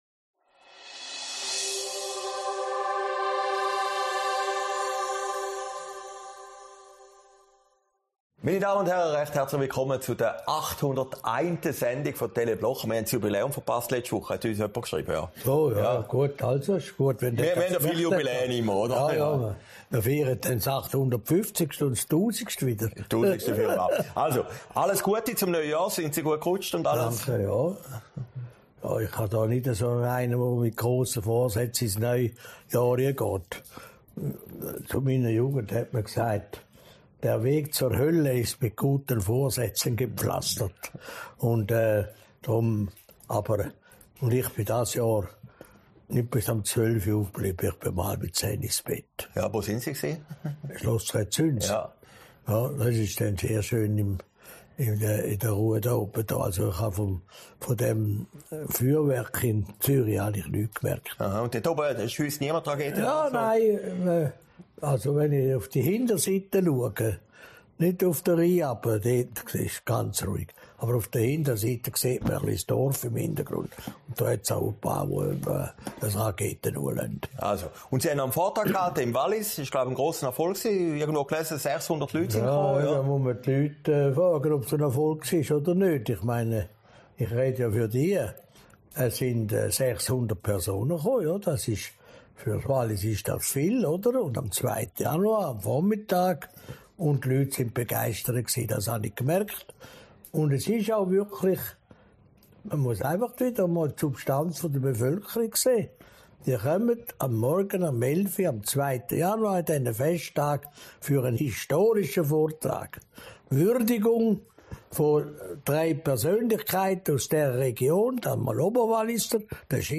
Januar 2023, aufgezeichnet in Bad Horn